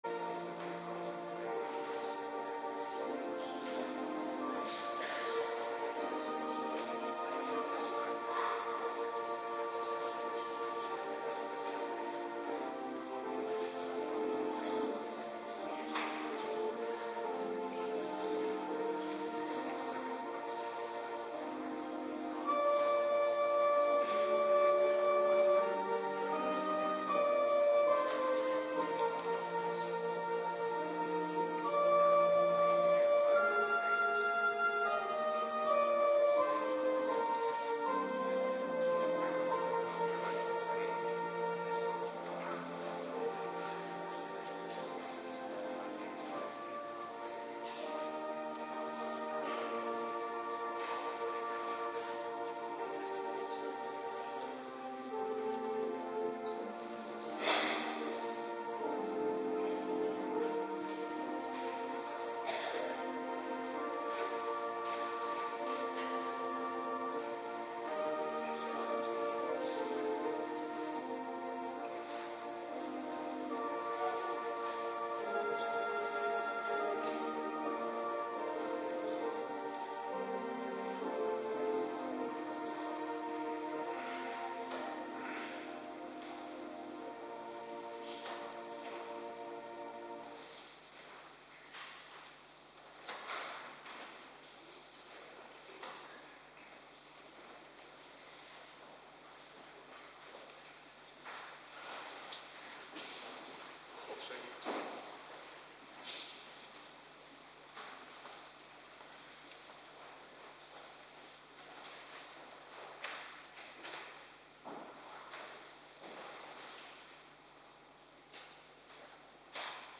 Morgendienst - Cluster 2
Locatie: Hervormde Gemeente Waarder